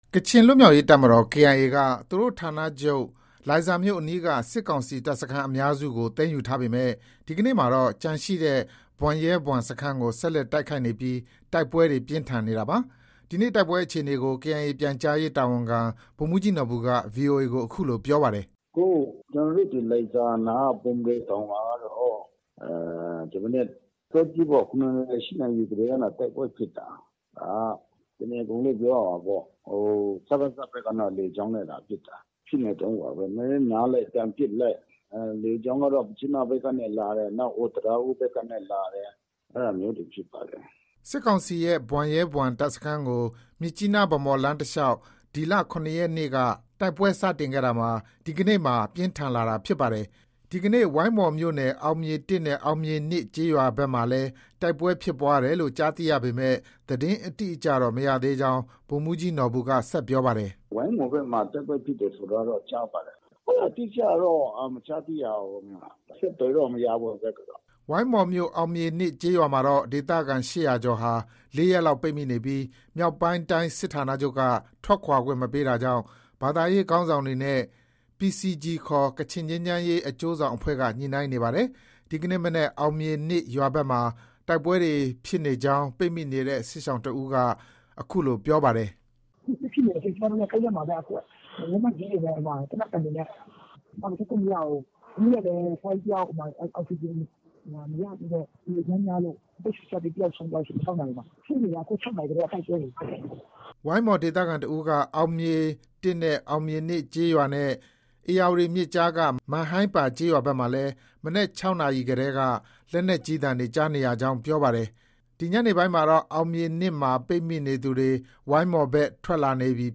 ဝိုင်းမော်မြို့နယ် အောင်မြေ ၂ ကျေးရွာမှာတော့ ဒေသခံ ၈၀၀ ကျော်ဟာ ၄ ရက်လောက် ပိတ်မိနေပြီး မြောက်ပိုင်းတိုင်းစစ်ဋ္ဌာနချုပ်က ထွက်ခွာခွင့်မပေးတာကြောင့် ဘာသာရေးခေါင်းဆောင်တွေနဲ့ PCG ခေါ် ကချင်ငြိမ်းချမ်းရေးအကျိုးဆောင်အဖွဲ့က ညှိနှိုင်းနေပါတယ်။ ဒီကနေ့ မနက် အောင်မြေ ၂ရွာဘက်မှာ တိုက်ပွဲတွေ ဖြစ်နေကြောင်း ပိတ်မိနေတဲ့ စစ်ရှောင်တဦးက အခုလို ပြောပါတယ်။